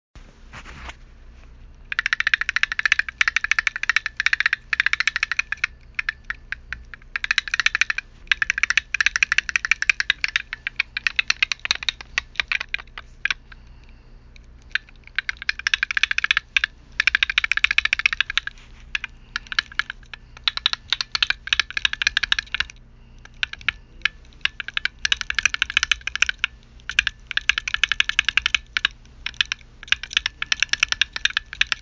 Field Recording 8
Location: Hauge House, Room 314
Sound: Opening of various zippers on a backpack